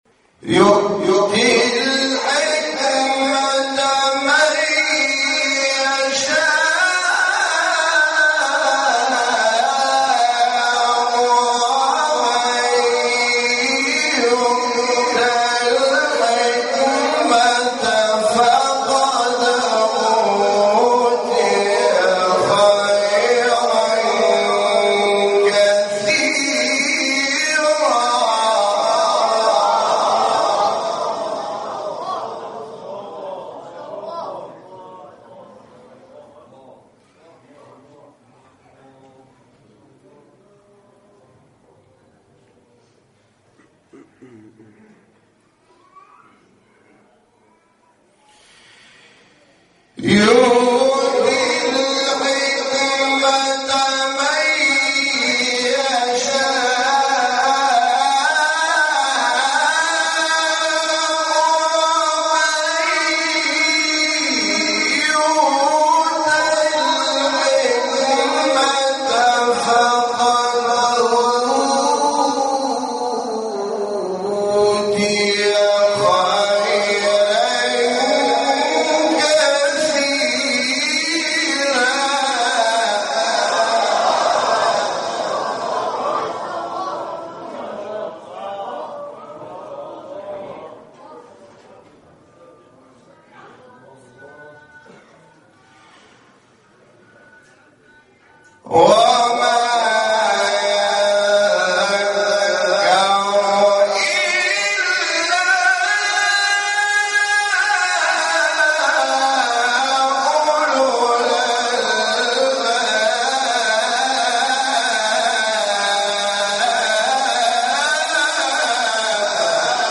آیه 269-270 سوره بقره با صدای حامد شاکرنژاد | نغمات قرآن | دانلود تلاوت قرآن